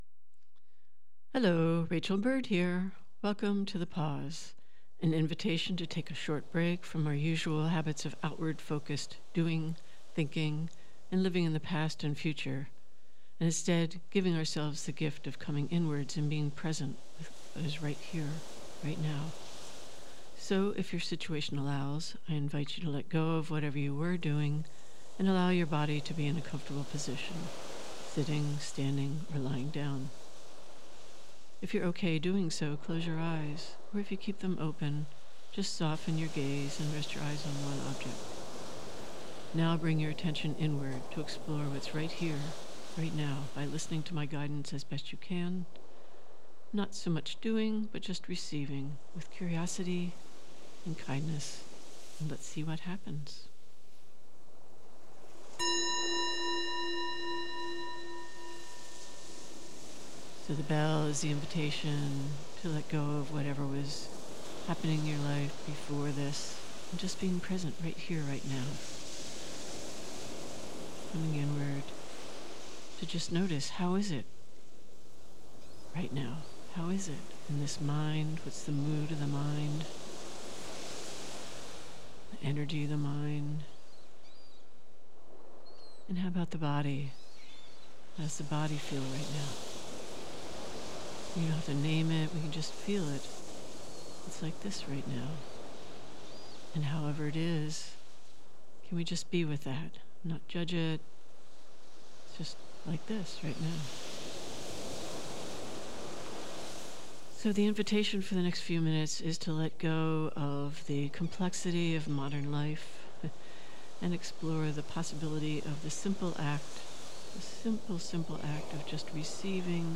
Meditation practice of simple awareness of sense experience.